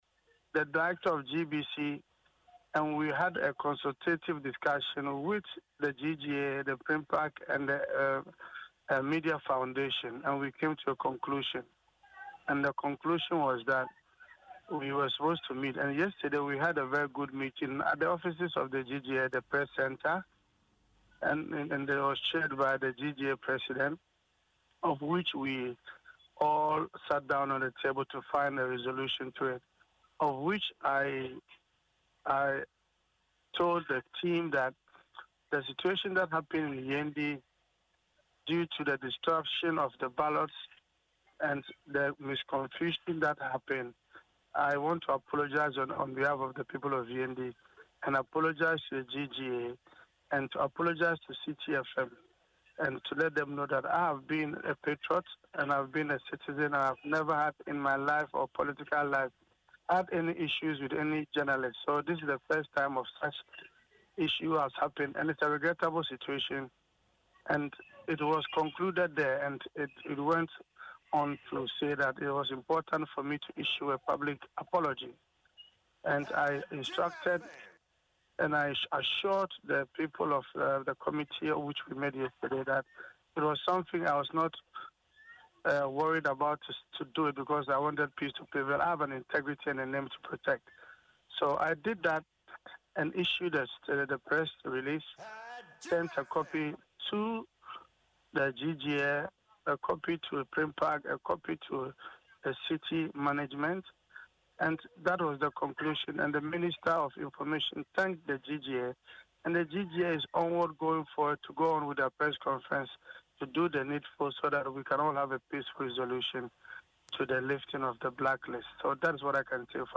But Mr. Aliu Mahama on the same show said he dispatched the apology letter to management of Citi on Tuesday at 5: pm.